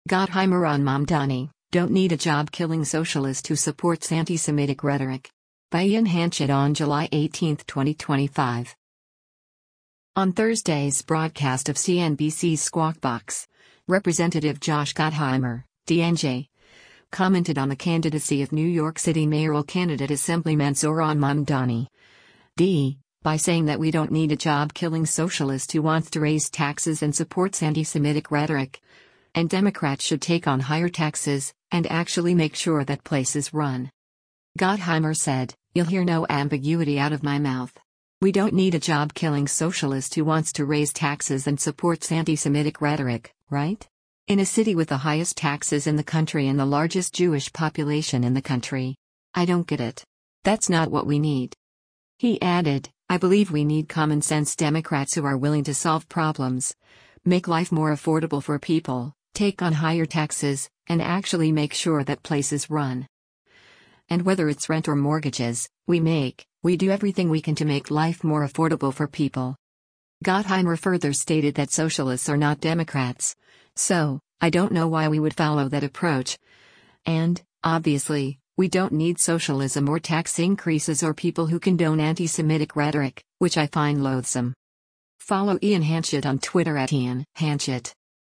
On Thursday’s broadcast of CNBC’s “Squawk Box,” Rep. Josh Gottheimer (D-NJ) commented on the candidacy of New York City mayoral candidate Assemblyman Zohran Mamdani (D) by saying that “We don’t need a job-killing socialist who wants to raise taxes and supports antisemitic rhetoric,” and Democrats should “take on higher taxes, and actually make sure that places run.”